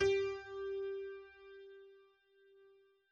Ton "G"